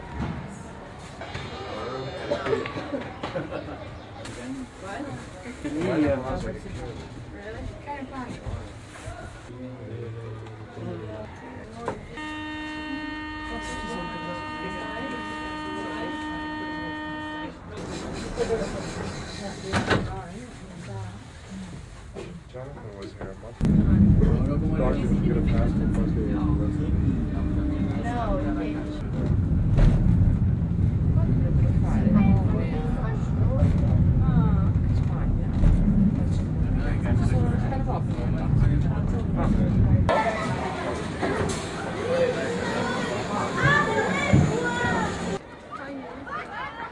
描述：巴黎索道缆车，外部和内部。
Tag: 公共交通 缆车 氛围 行人 巴黎 城市